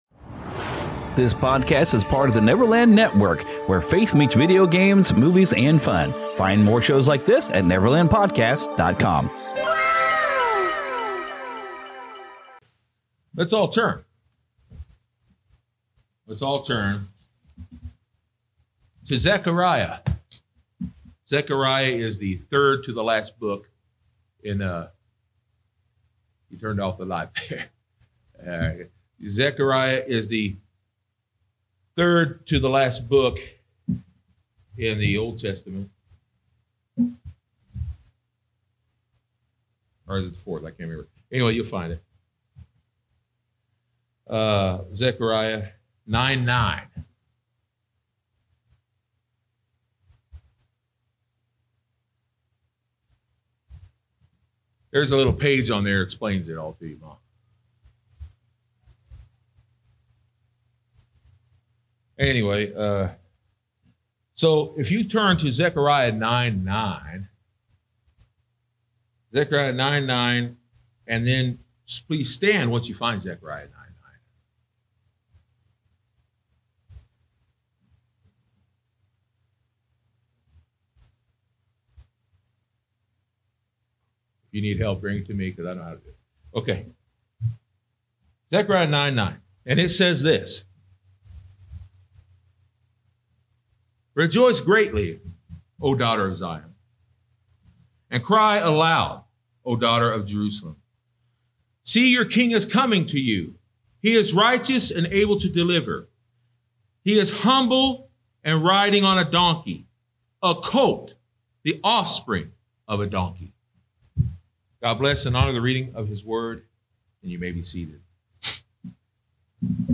Sermons from the Moment of Truth Bible Baptist Church in Claycomo, Missouri (Kansas City area)